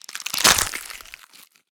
bone_breaking_03.wav